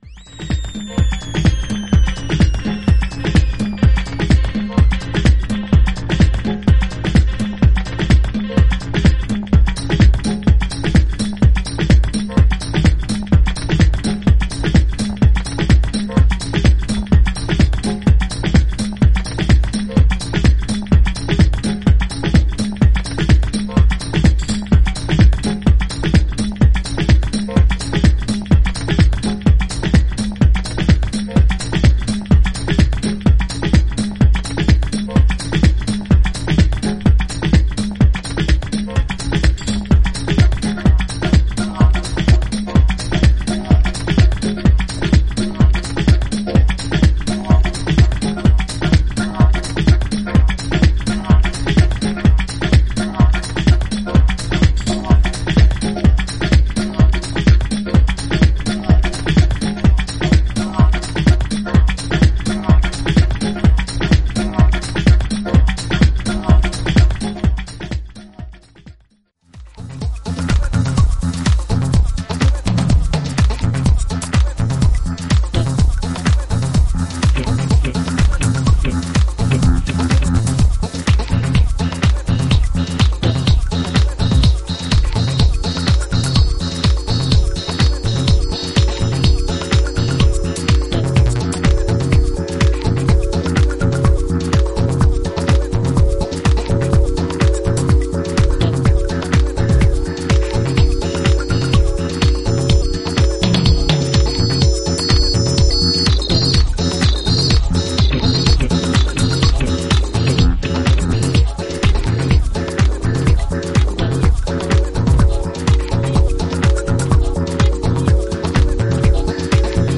ディスコ・サンプルのイントロから、ミニマルなベースラインやフリーキーなシンセが絡み、徐々にビルドアップする